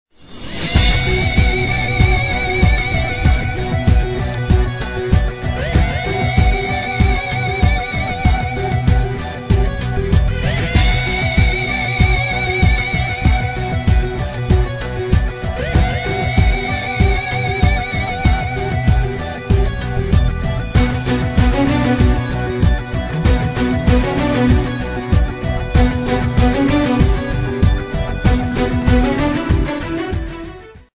pop balada, el. gitara solo